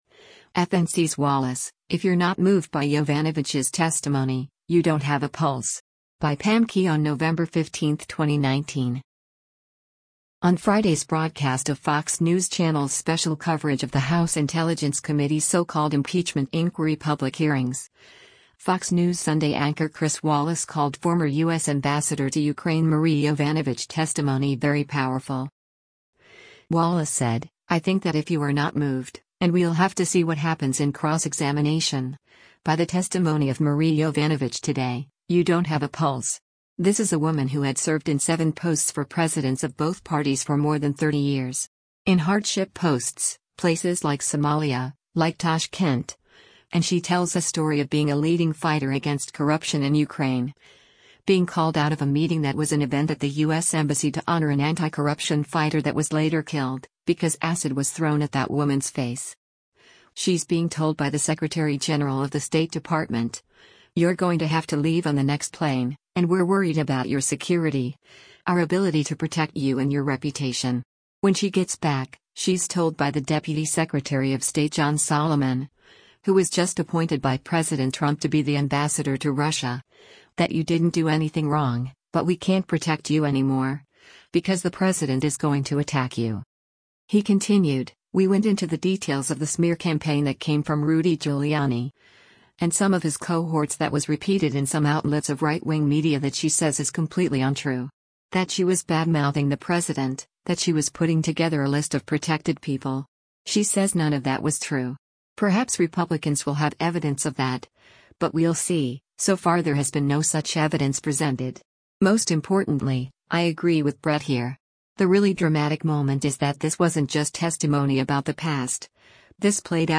On Friday’s broadcast of  Fox News Channel’s special coverage of the House Intelligence Committees so-called impeachment inquiry public hearings, “Fox News Sunday” anchor Chris Wallace called former U.S. Ambassador to Ukraine Marie Yovanovitch testimony “very powerful.”